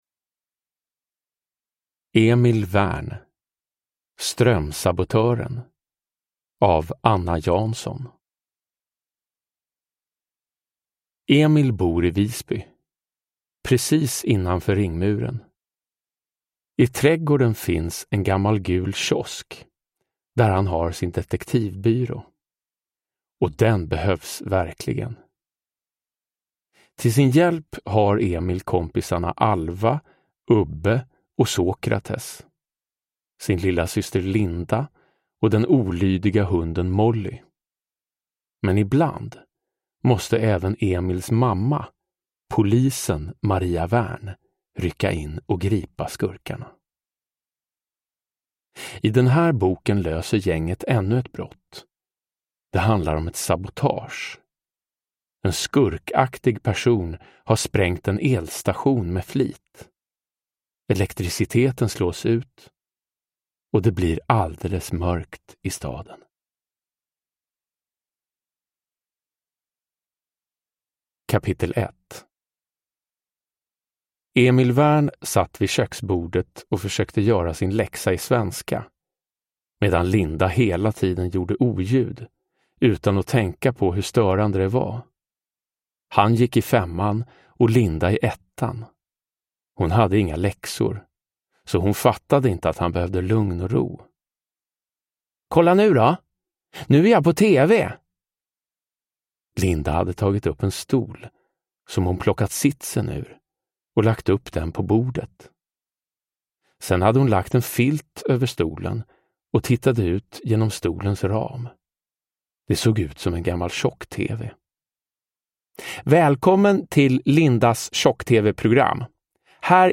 Strömsabotören – Ljudbok
Uppläsare: Jonas Karlsson